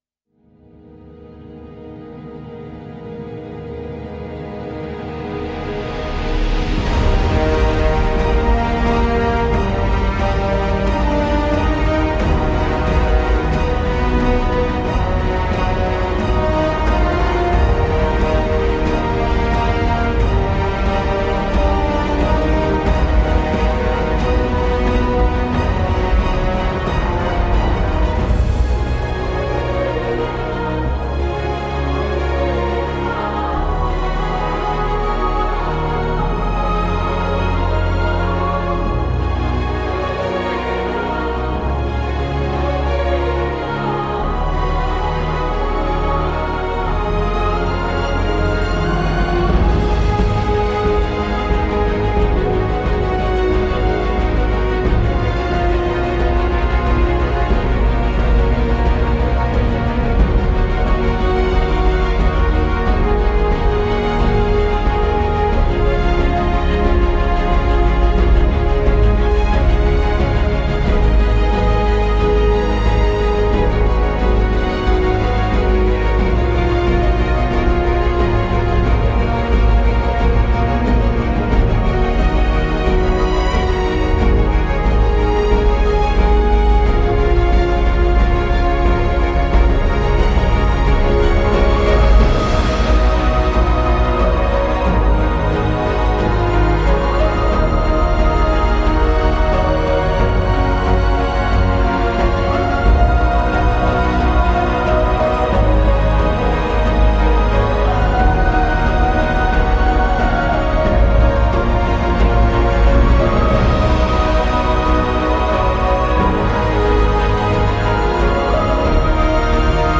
Efficace, oui : comme un robot jetable.